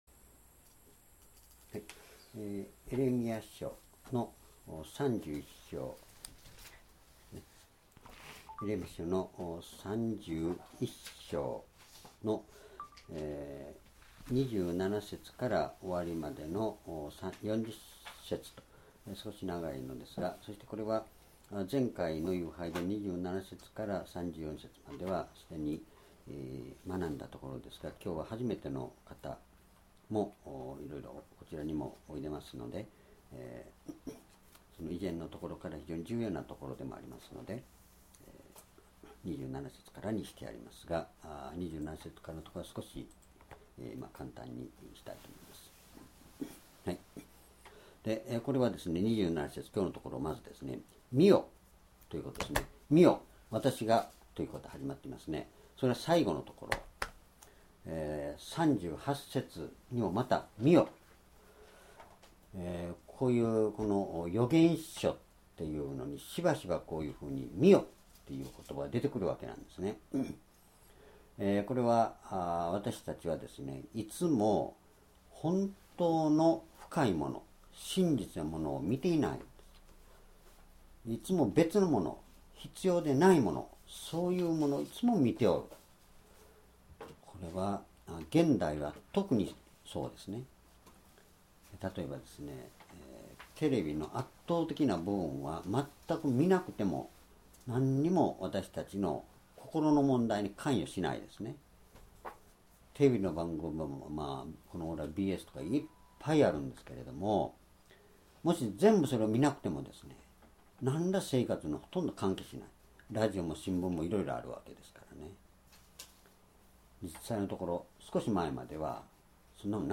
主日礼拝日時 2017年4月24日（移動） 聖書講話箇所 エレミヤ書31章27-40 「再建されるとき」 ※視聴できない場合は をクリックしてください。